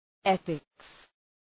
Shkrimi fonetik {‘eɵıks}